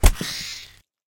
Spider_die2.ogg